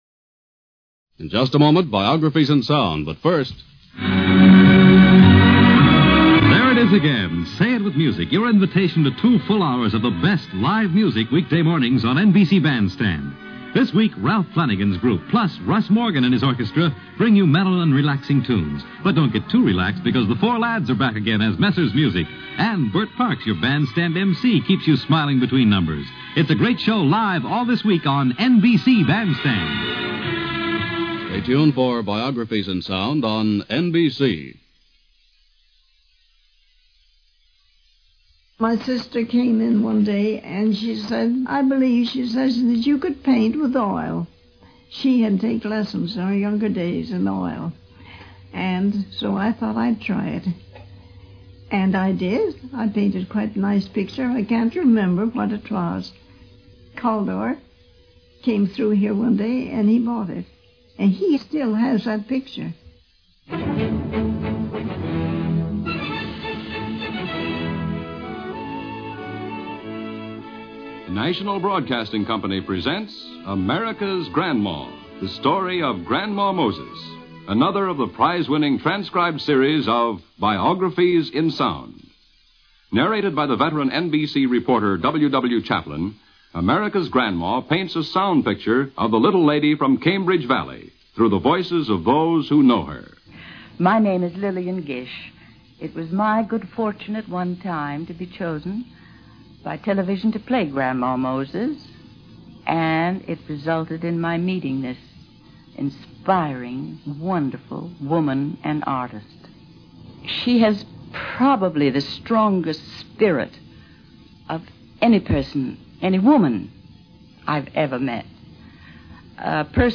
Featuring Vice President Richard M. Nixon